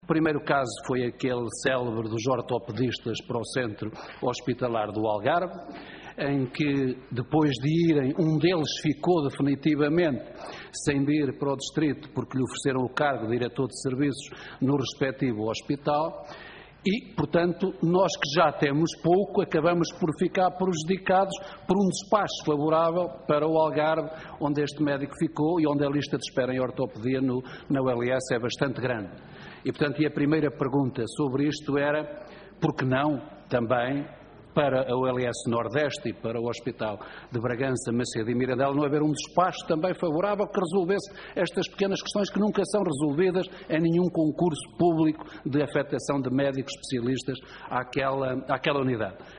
José Silvano, em declarações captadas pelo canal do Parlamento, pede um despacho semelhante ao dado ao Centro Hospitalar do Algarve, que aprove a mobilidade de profissionais para suprir eventuais carências.